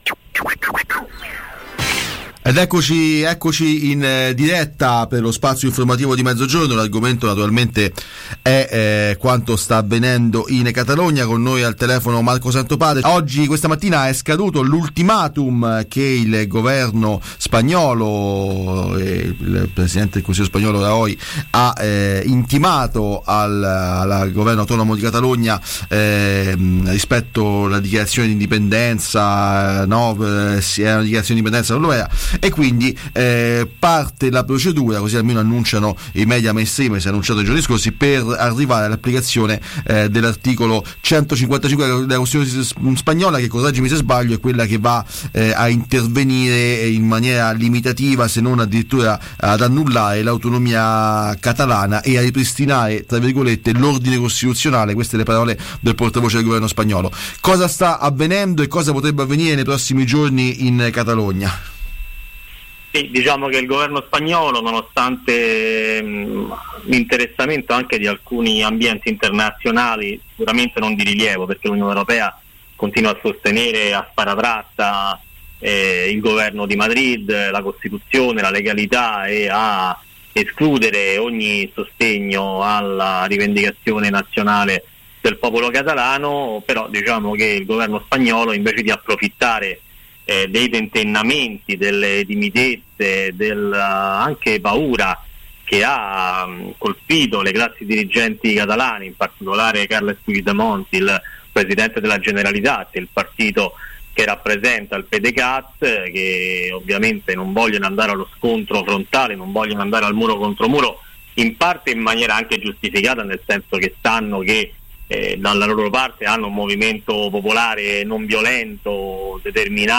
Catalunya, Madrid verso la sospensione dell’autonomia: intervista